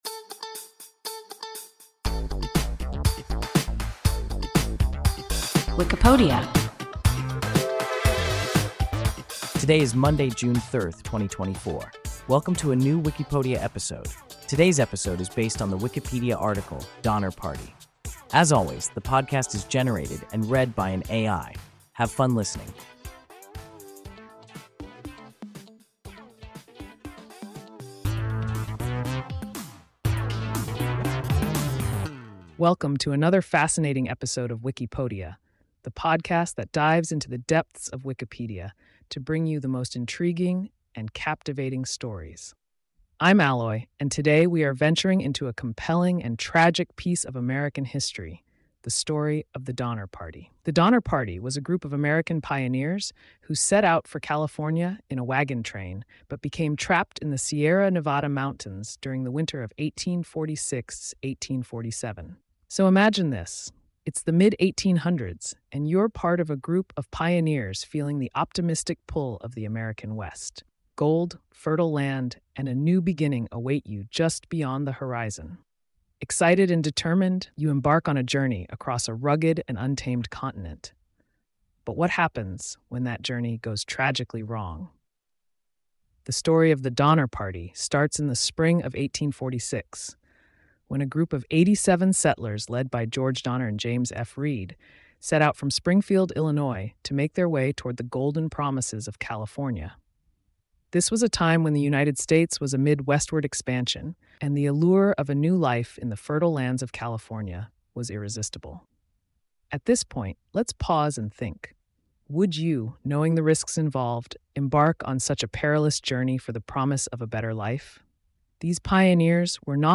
Donner Party – WIKIPODIA – ein KI Podcast